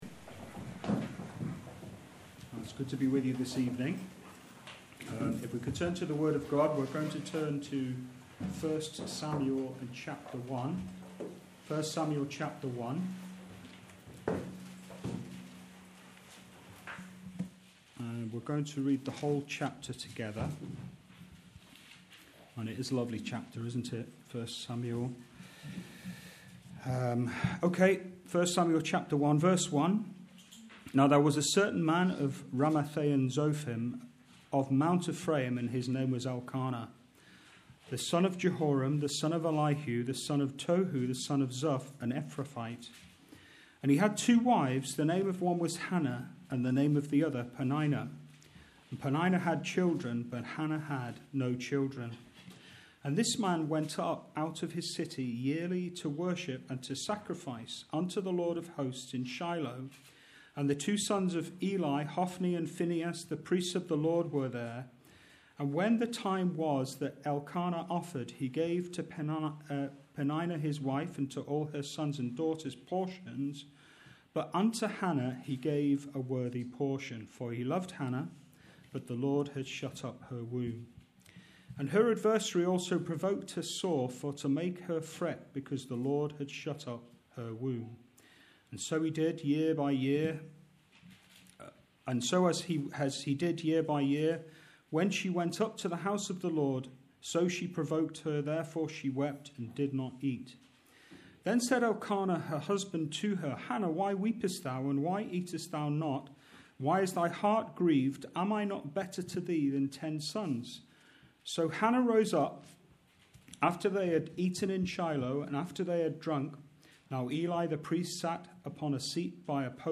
A recording of our Regular Saturday Night Ministry Meeting.